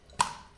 household
Opening Cap of Can of Shaving Foam 2